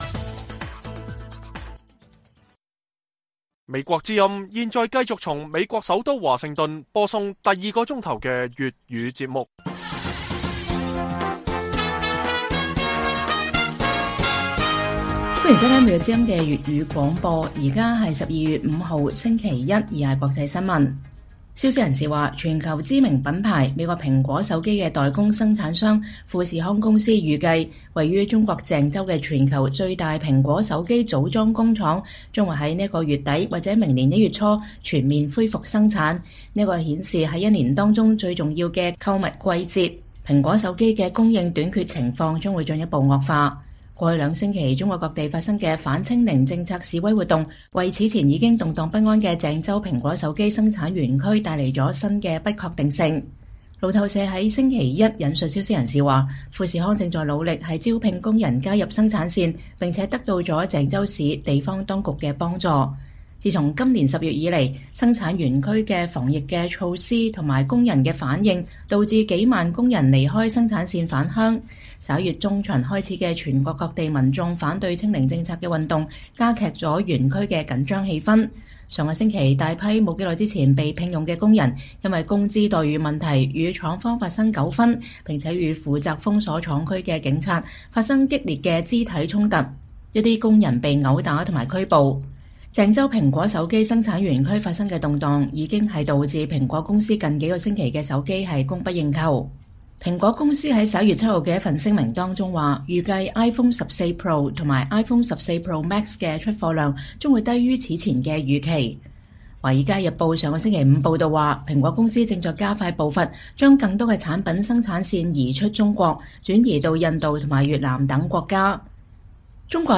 粵語新聞 晚上10-11點: 美國謹慎注視沙特本週主辦的中阿峰會